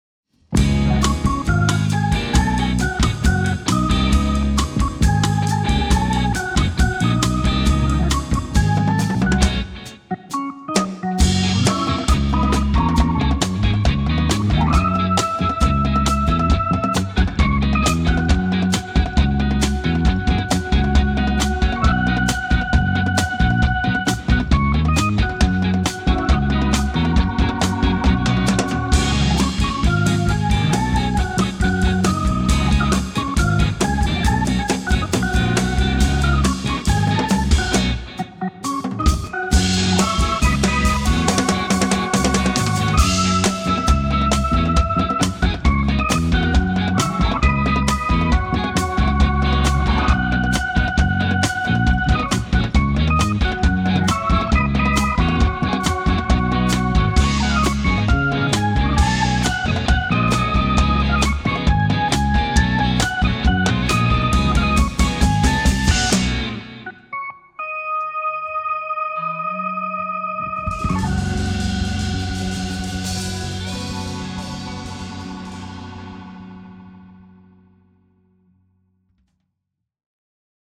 Vi spelar instrumental cool funk i 60/70-tals stil.
Kvartett; elgitarr, elbas, hammond orgel och livetrummor.